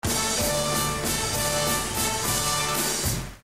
tusch1.mp3